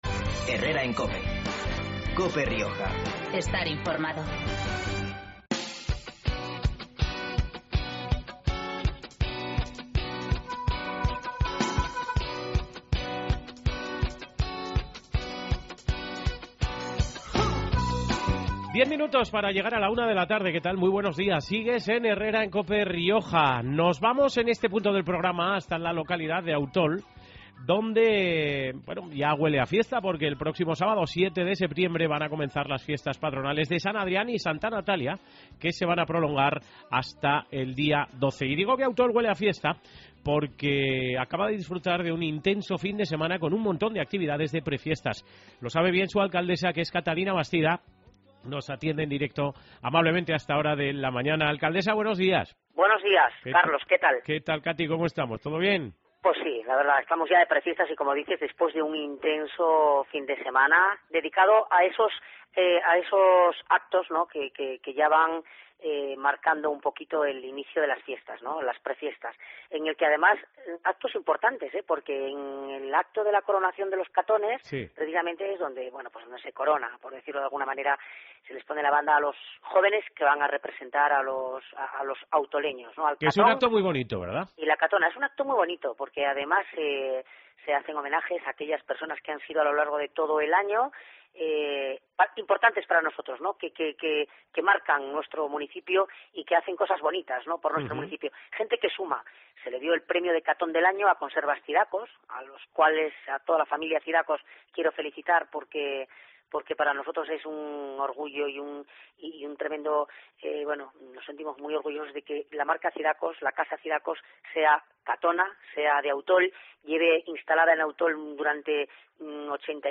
Herrera en Cope Rioja Baja (lunes, 2 septiembre. 12:50-13:00 horas) Hoy con Catalina Bastida, alcaldesa de Autol